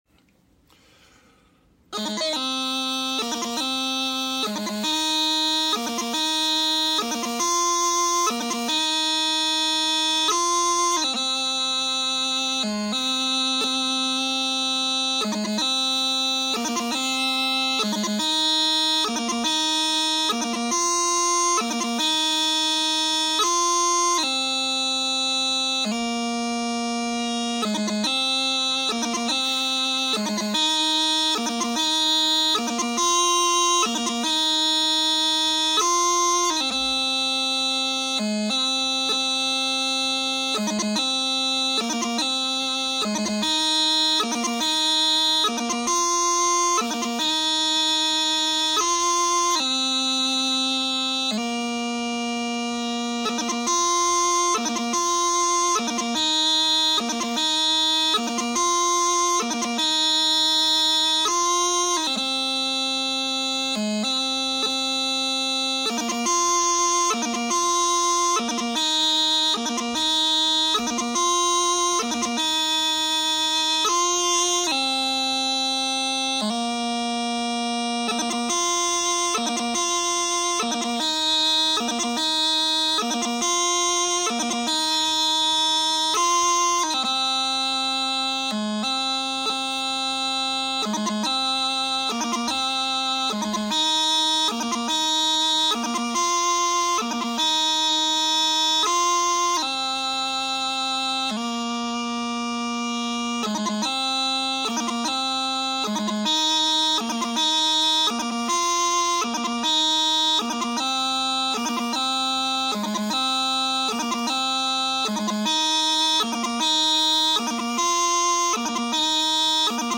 Below the tune I play three excerpts on the practice chanter to let people hear how it goes.